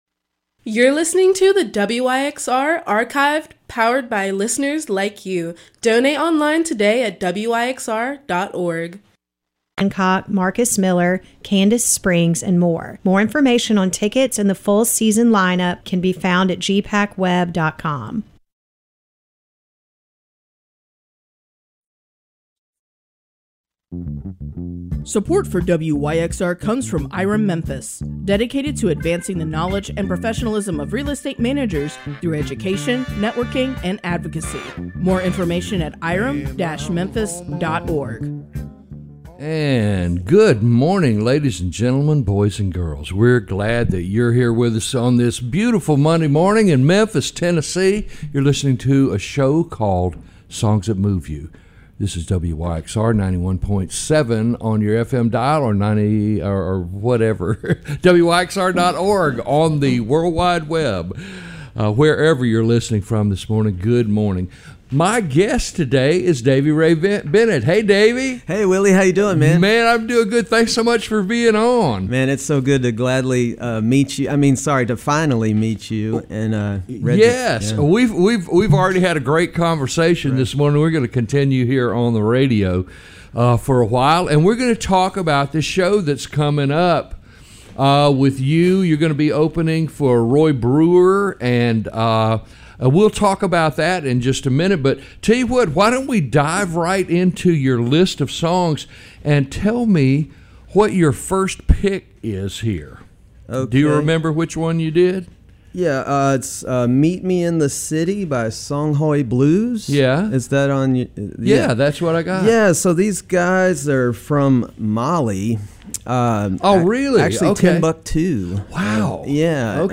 Talk Interview